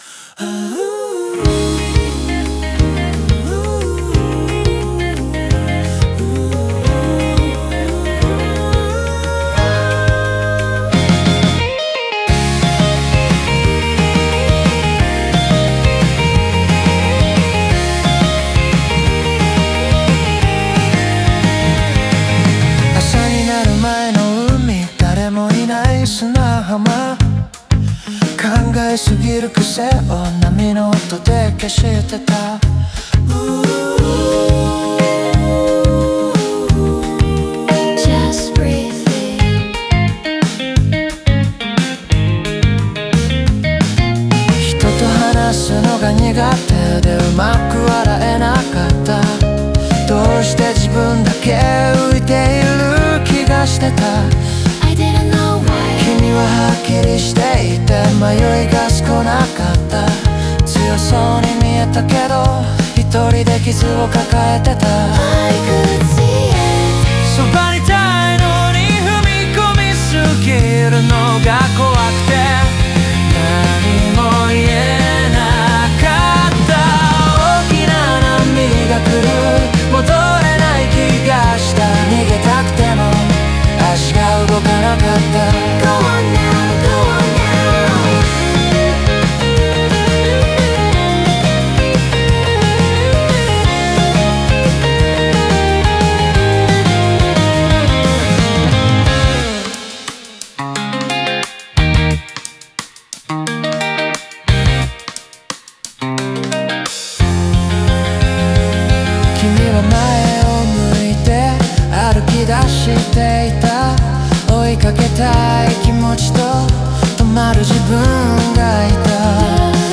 エレキギターを前面に押し出すことで、波に立ち向かう決意やクライマックスの感情の高まりをリズムと音の力で表現している。
サビではドライブ感のあるギターとバンド演奏が力強く感情を押し上げ、主人公の覚悟や決断を強調する。
エレキギター主体の躍動感とシンプルな言葉の組み合わせにより、物語が歌詞だけで自然に理解できる仕上がりとなっている。